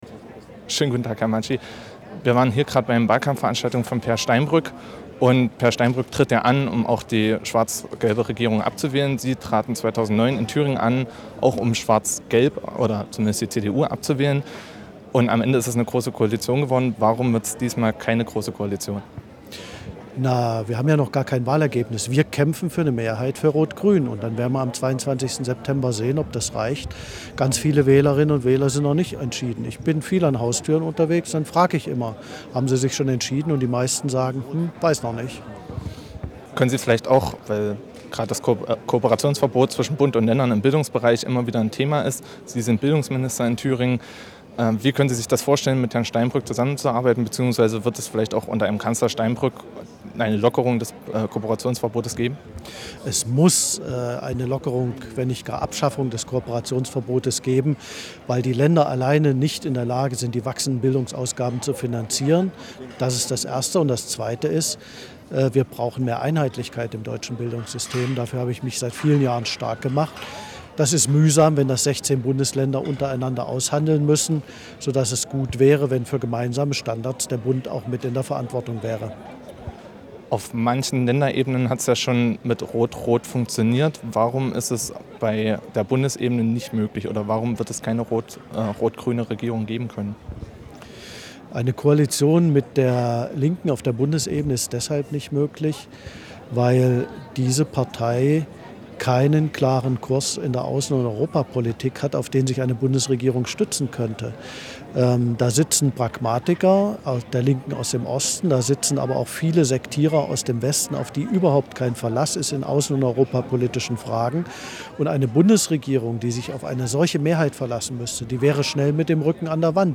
Interview mit Bildungsminister Christoph Matschie
Am Rande der Wahlkampfveranstaltung von Peer Steinbrück am Erfurter Domplatz stand uns Christoph Matschie Rede und Antwort. Der SPD-Landesvorsitzende sprach mit uns über die Chancen seiner Partei bei der kommenden Bundestagswahl, mögliche Koalitionspartner und das Kooperationsverbot zwischen Bund und Ländern im Bildungsbereich.